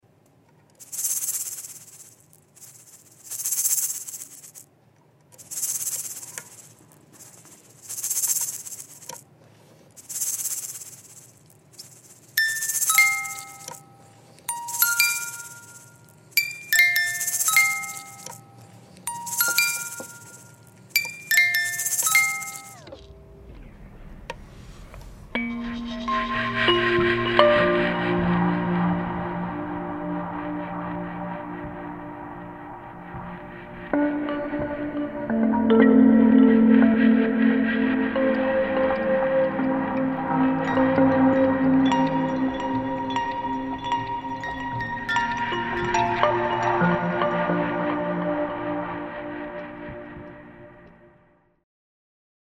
Recording a shaker and a sound effects free download
Recording a shaker and a tiny music box that has approximately 10 usable notes with Microcosm's looper to create what can only be described as peaceful noises